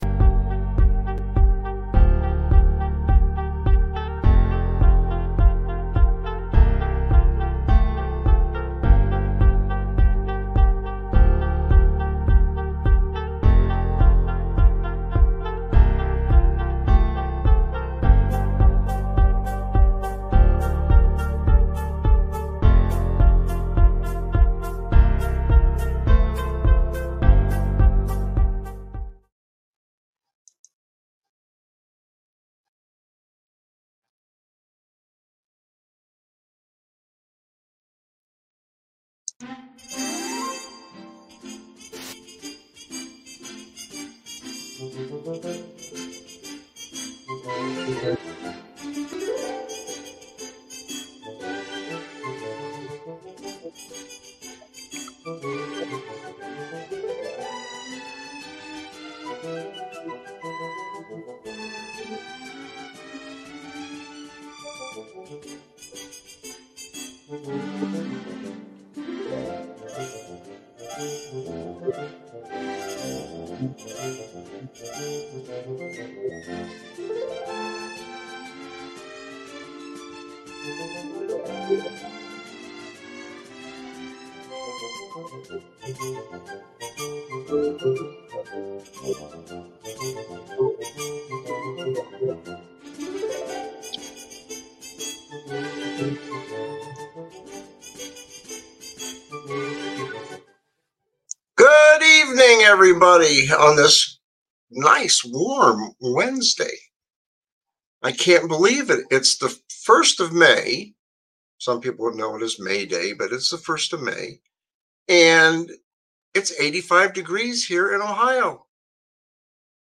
My World Live, Laff, Whatever is a satirical talk show that tackles the absurdities of life with a healthy dose of humor.
No topic is off-limits, and his guests, a rotating cast of comedians, commentators, and everyday folks, add their own unique perspectives to the mix.